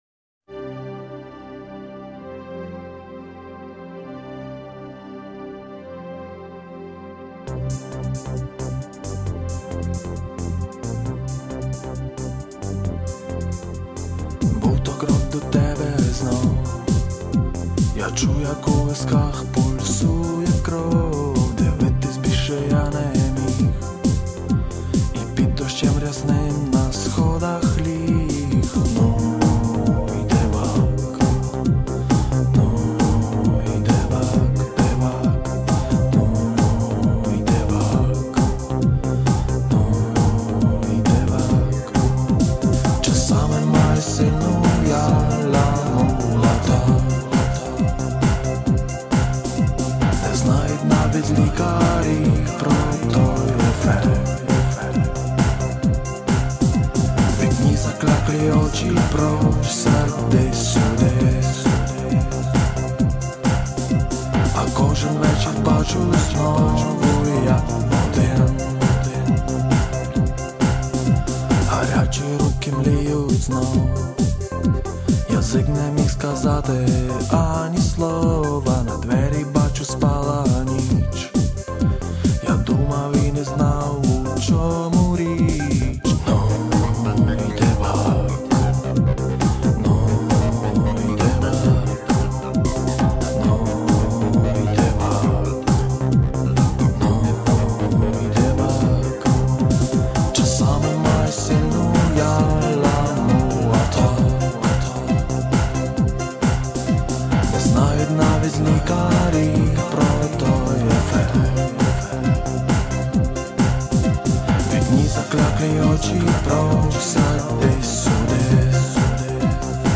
c: pop